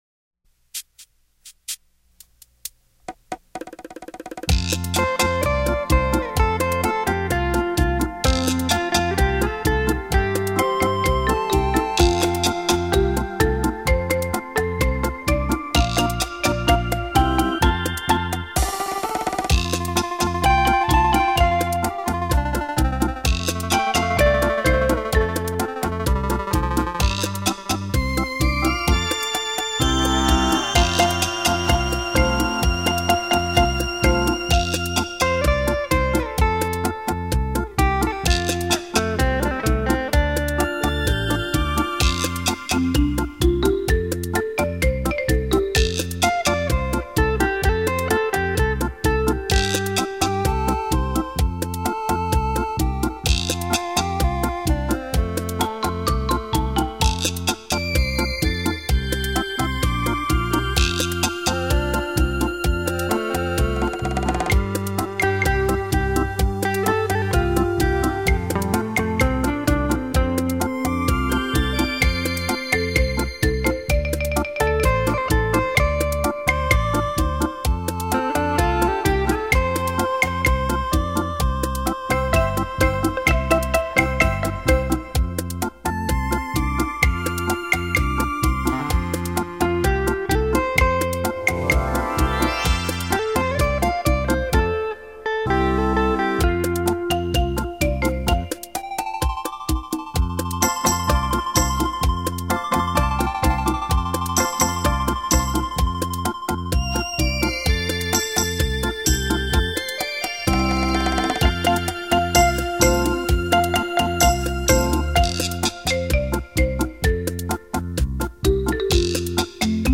超强环绕音效
冲击力超强。